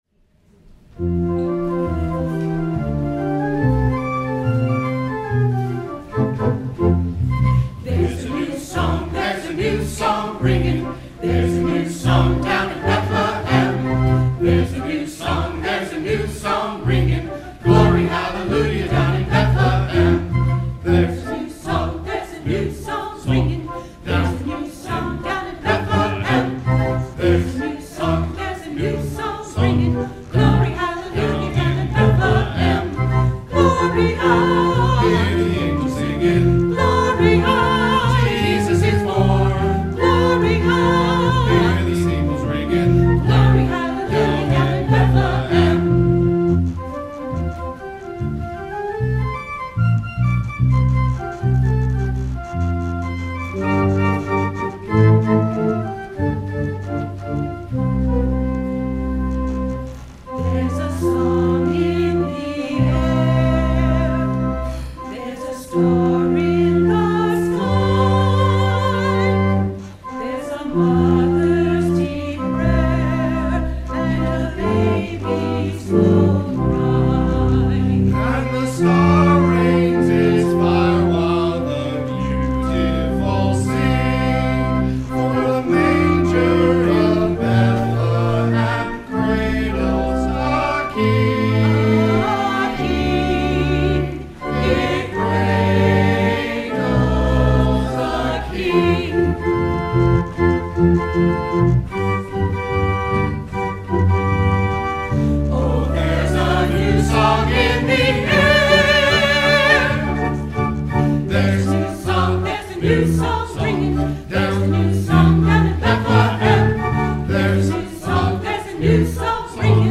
The Algiers UMC Choir